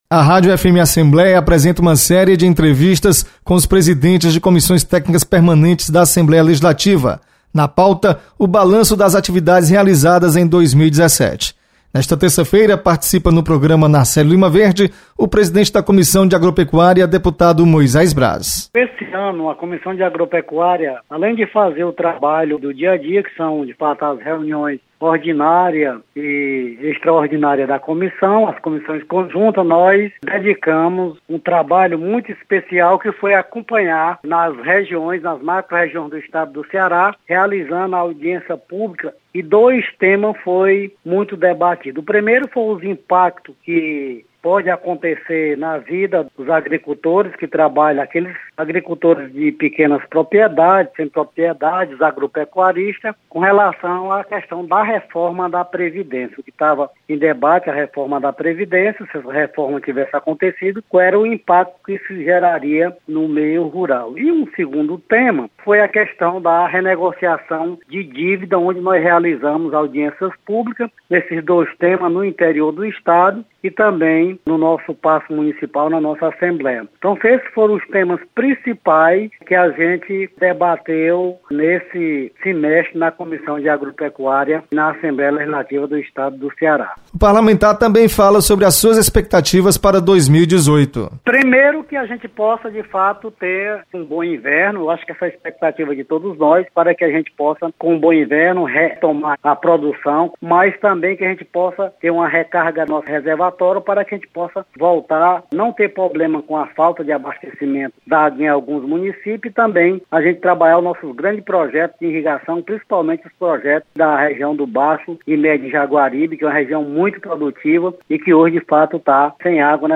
Deputado Moisés Braz apresenta balanço da Comissão de Agropecuária